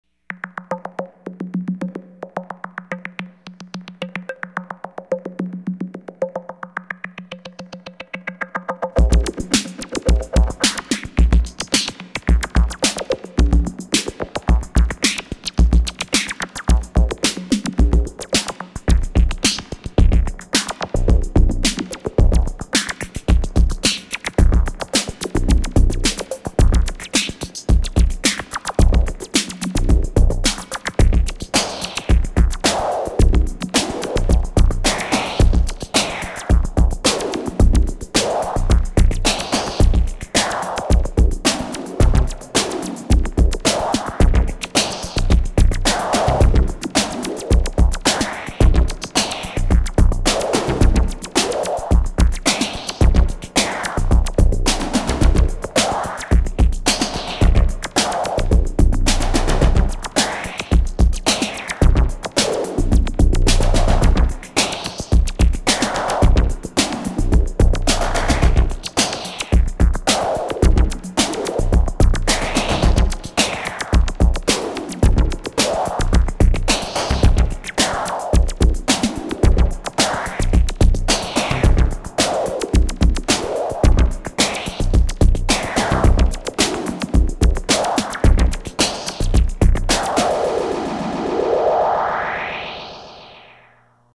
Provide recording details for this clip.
recorded in one pass.